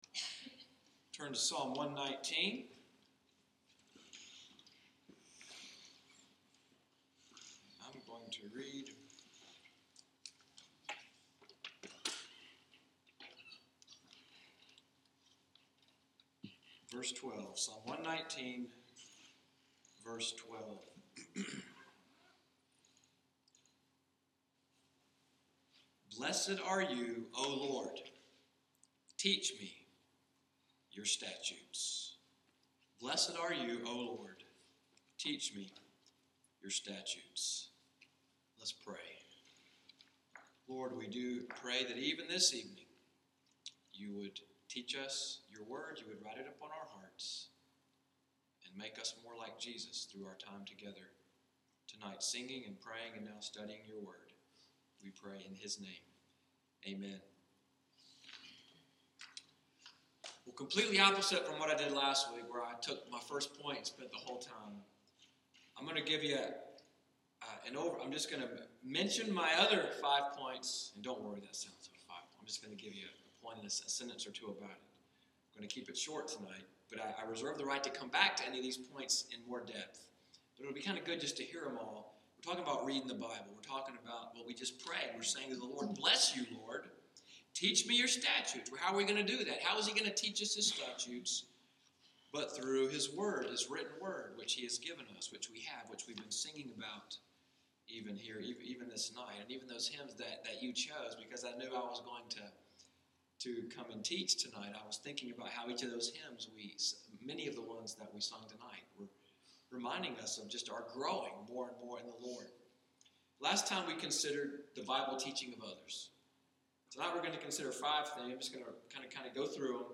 EVENING WORSHIP at NCPC Discipleship sermon, “Learning Your Bible,” March 13, 2016.